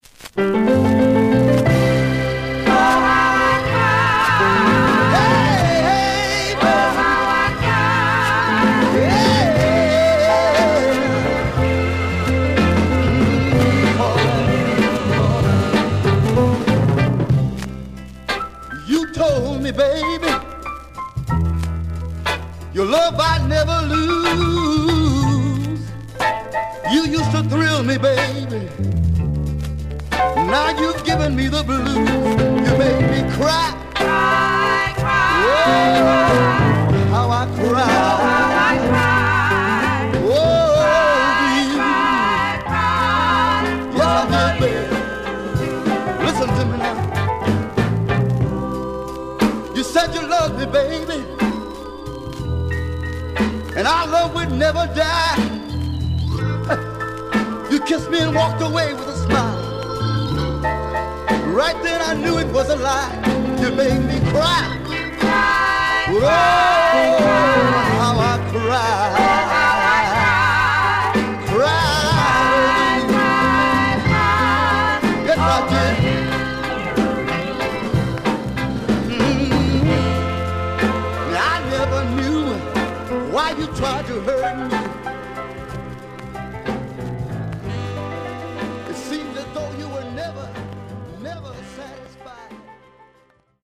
Surface noise/wear Stereo/mono Mono
Soul